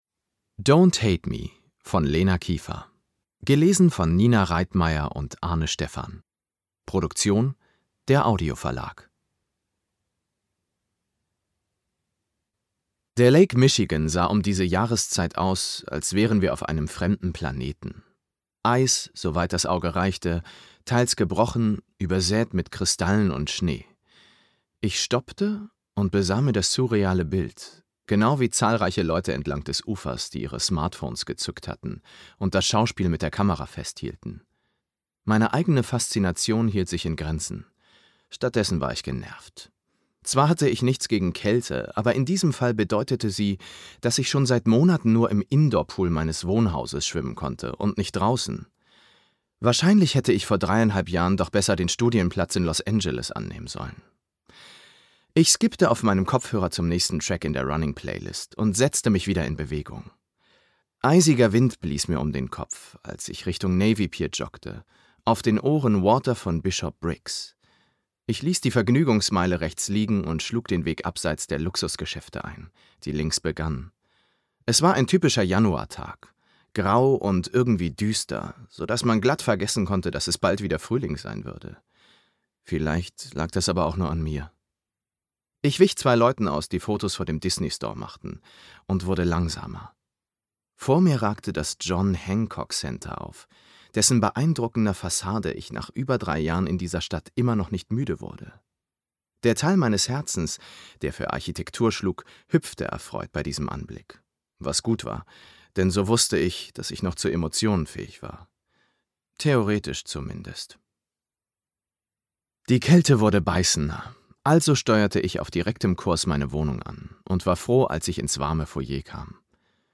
Lese- und Medienproben
Don't HATE me (Teil 2) Ungekürzte Lesung